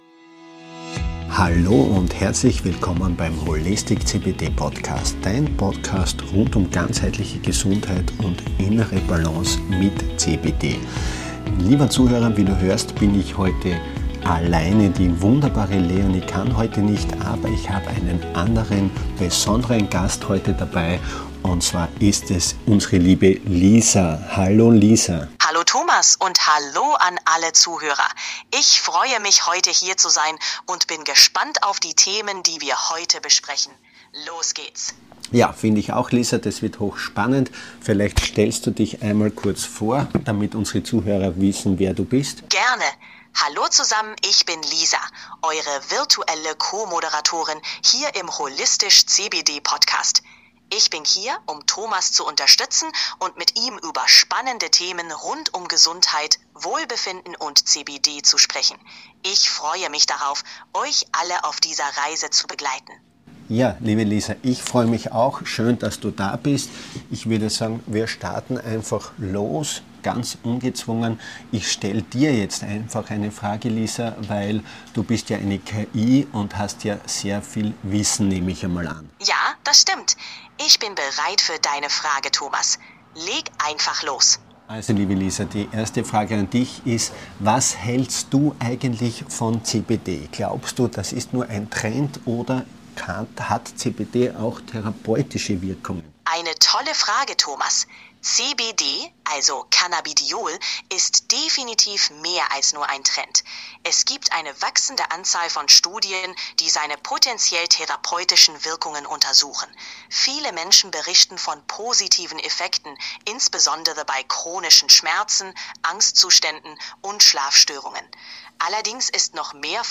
Ein spannender Austausch zwischen Mensch und Maschine über Themen, die uns alle beschäftigen.